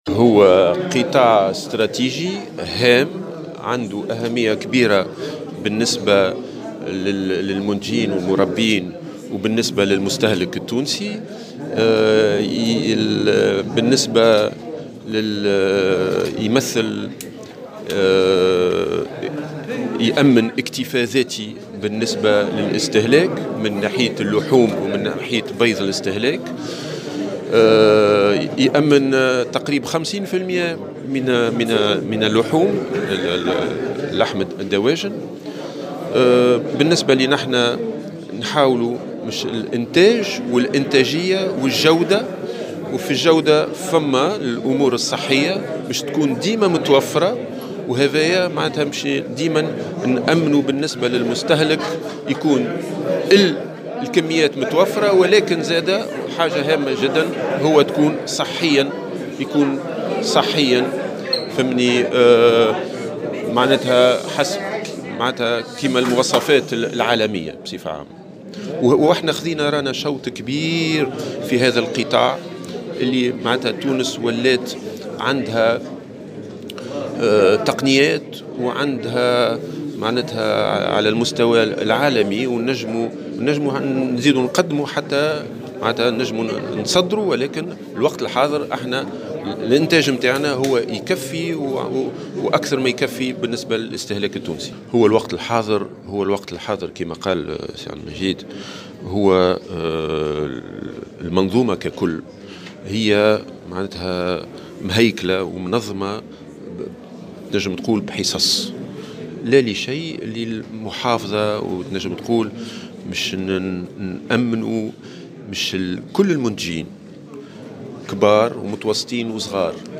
أكد وزير الفلاحة محمود الياس حمزة، في تصريح لمراسلة الجوهرة أف أم، أن قطاع الدواجن 50 % من اللحوم في تونس، مشددا على أن هذا القطاع يعد استراتيجيا اذ يؤمن الاكتفاء الذاتي لتونس على مستوى استهلاك اللحوم والبيض.
وأوضح خلال اليوم الاعلامي الذي ينظمه المجمع المهني المشترك لمنتوجات الدواجن أن منظومة قطاع الدواجن مهيكلة ومنظمة بنظام الحصص لتكون الأسعار في المتناول، ما يمنع التوجه نحو التصدير، لافتا إلى أنه بالإمكان فتح حوار بهذا الشأن مع مختلف المتدخلين وأخذ القرار المناسب فيما يتعلق بالتصدير.